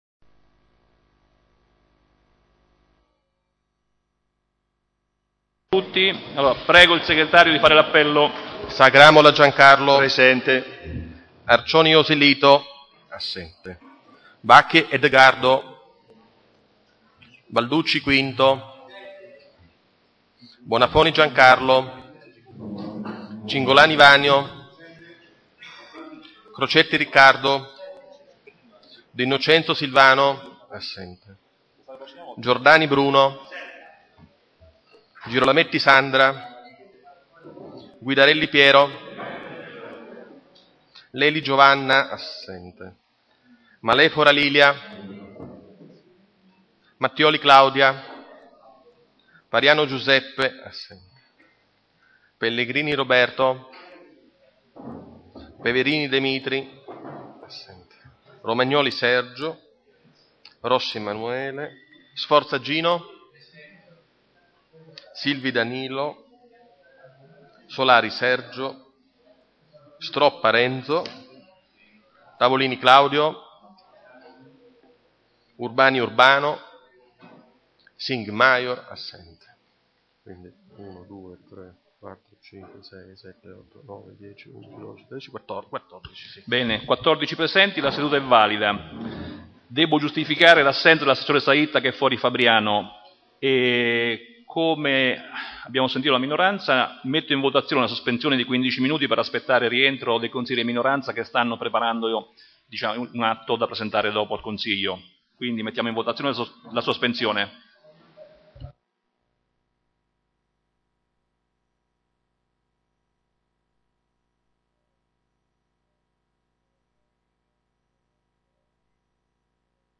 Ai sensi dell'art. 20, comma 7, dello Statuto Comunale e dell'articolo 14 del regolamento consiliare, il Consiglio Comunale è convocato presso Palazzo Chiavelli - sala consiliare domenica 5 giugno alle ore 9.30
SVOLGIMENTO DELLA SEDUTA AUDIO DELLA SEDUTA CRONOLOGIA DEL FILE AUDIO ORDINE DEL GIORNO DA CONSULTARE 1.